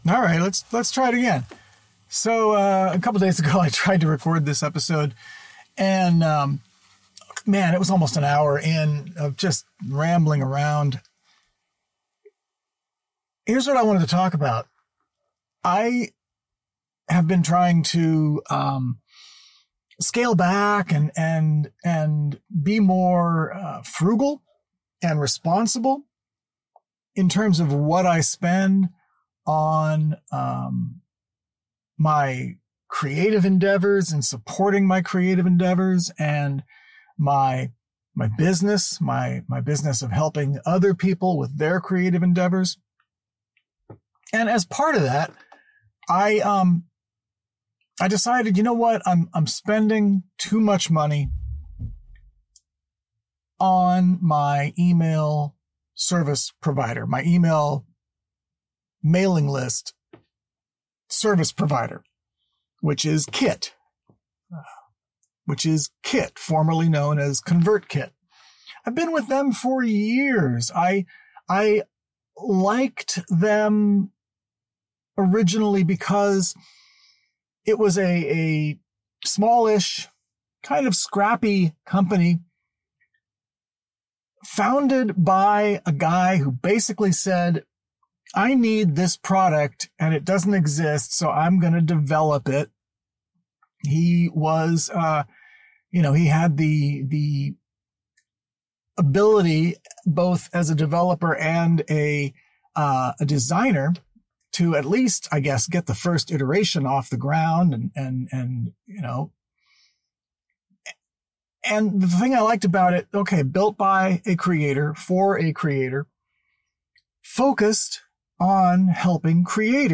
This episode was recorded on April 13, 2025, in the mobile studio of MWS Media in and around Huntington Beach, California.